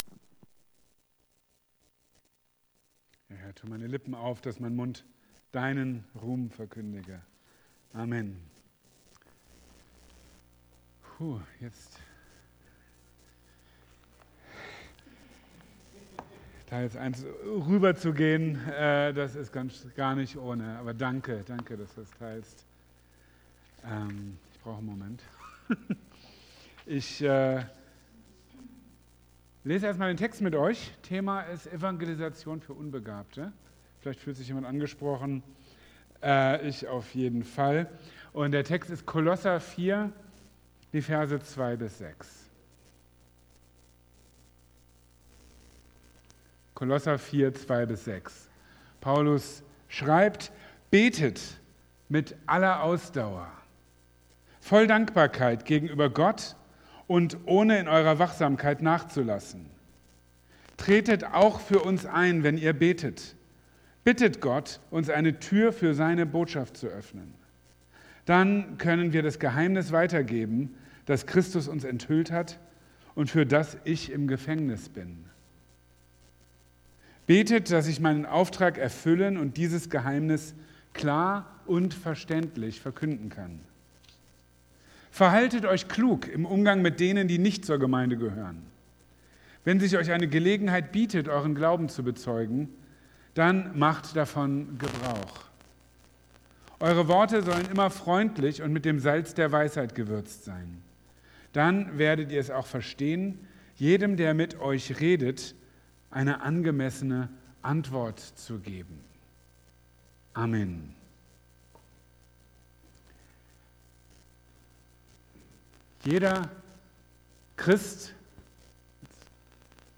Aber der Auftrag, Menschen zu Jüngern zu machen, gilt allen. Wie das zusammenpasst, zeigt er in seiner Predigt vom 30. März 2025 über Kolosser 4,2-6.